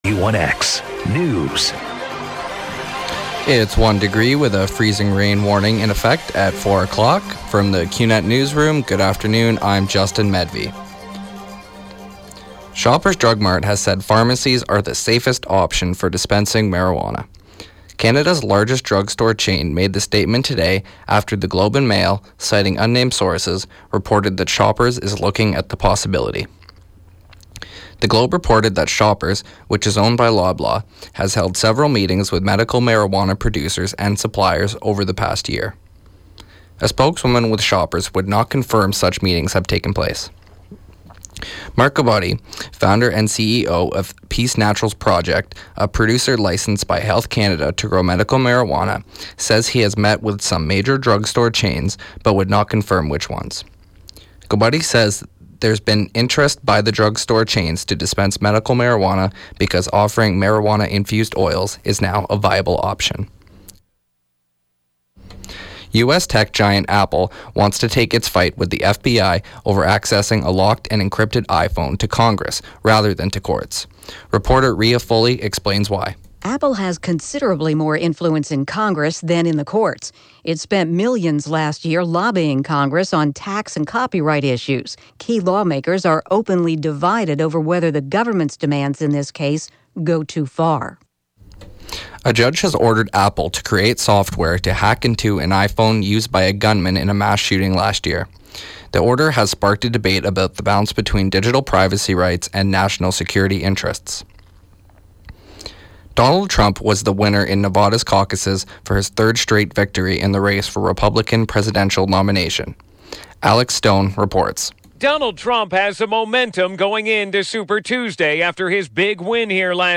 91X newscast – Wednesday, Feb. 24, 2016 –4 p.m.